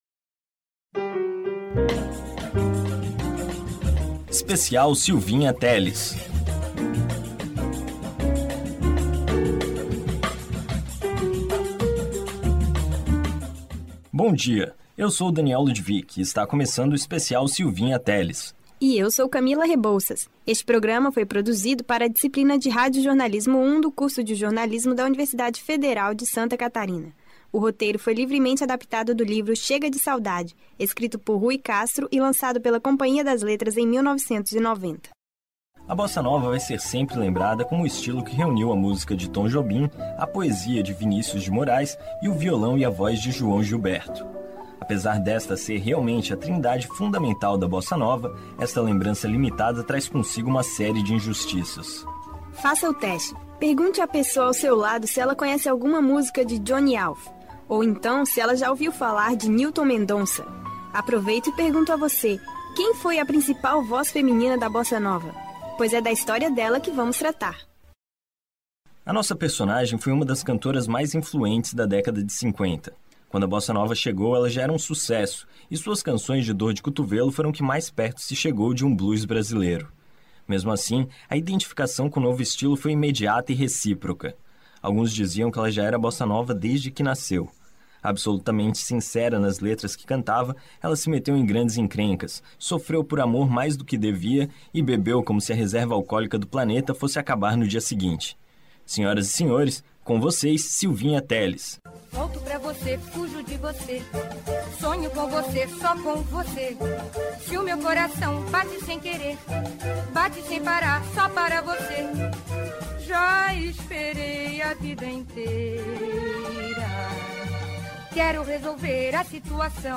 Um relato da segunda guerra mundial, contado por um ex-combatente da Força Expedicionária Brasileira que participou do conflito.
Radiojornalismo